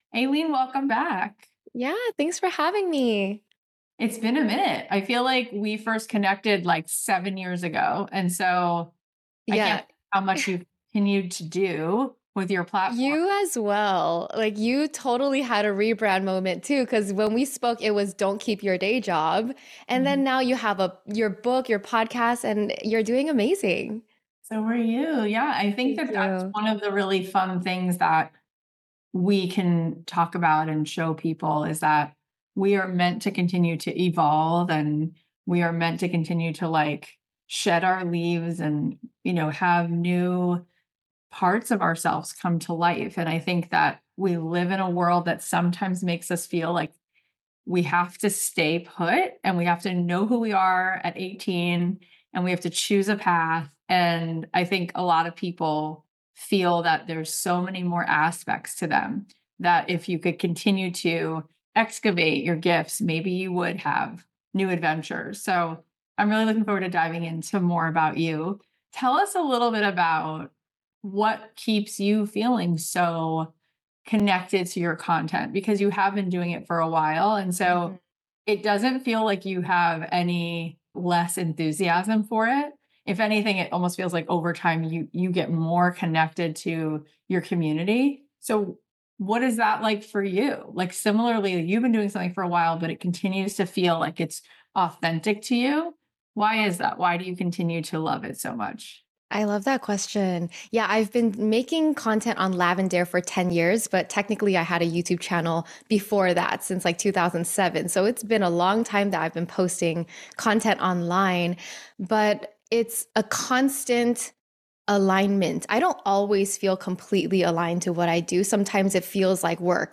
This heartfelt conversation is a reminder of the power in presence, beauty, and creating a life aligned with your truth.